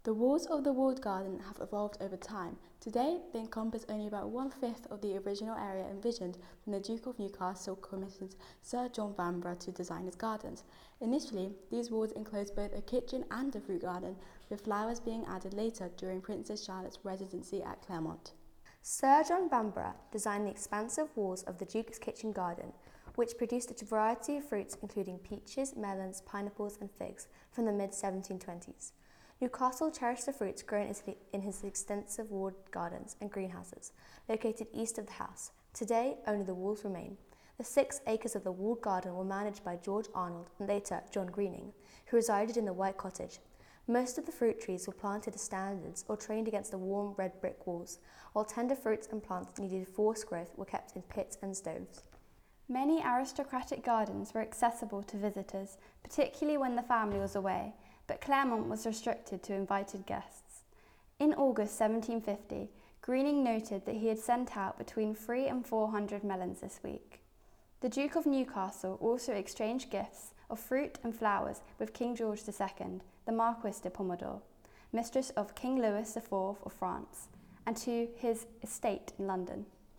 Heritage Audio Trail